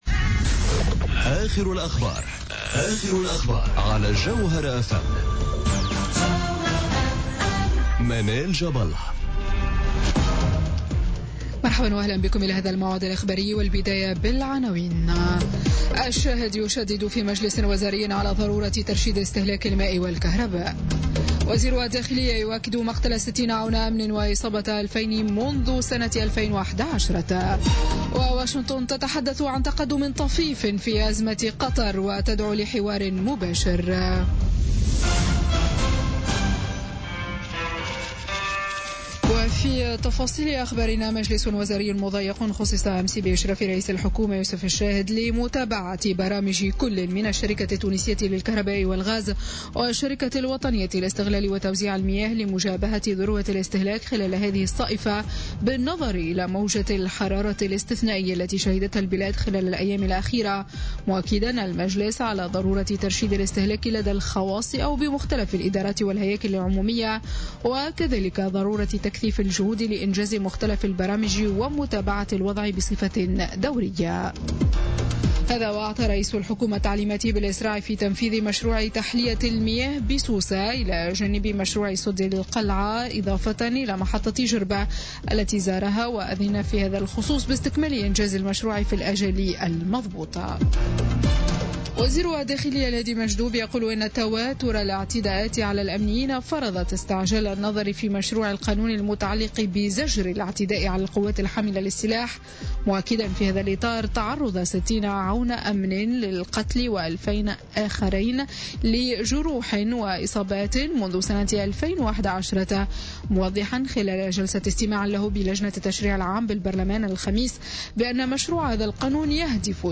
نشرة أخبار منتصف الليل ليوم الجمعة 14 جويلية 2017